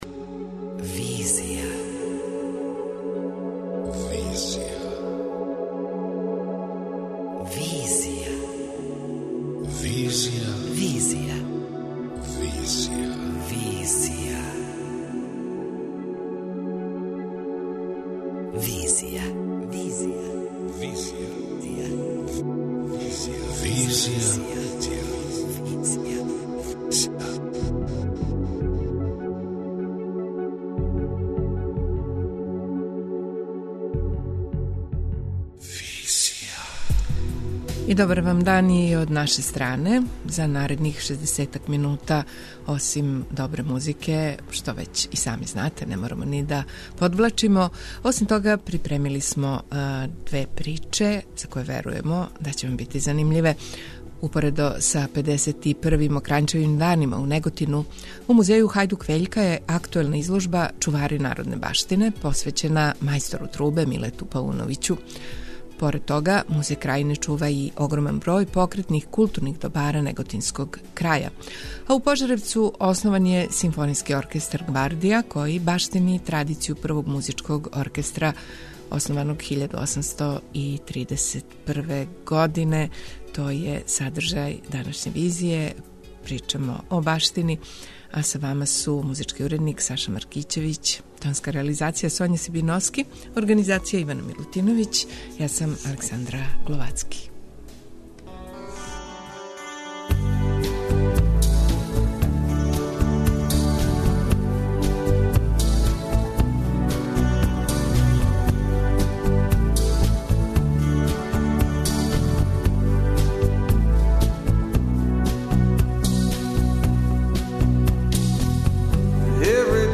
преузми : 28.06 MB Визија Autor: Београд 202 Социо-културолошки магазин, који прати савремене друштвене феномене.